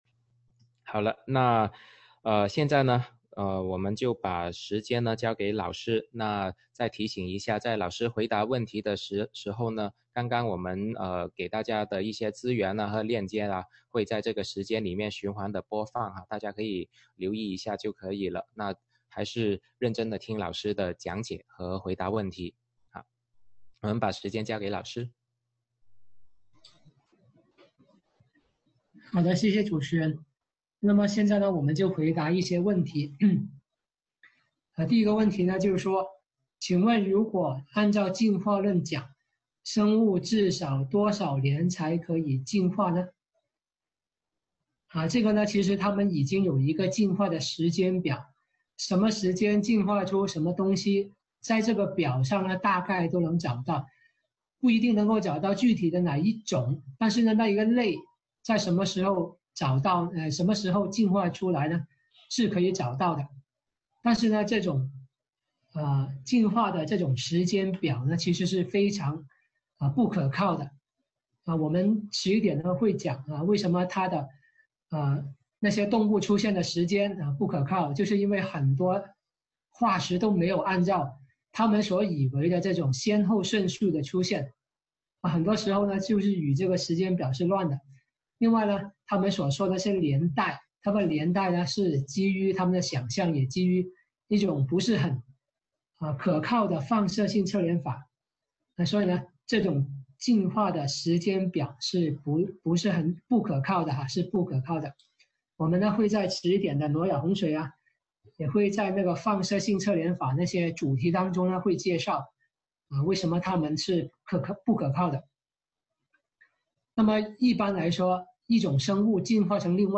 《化石记录》讲座直播回放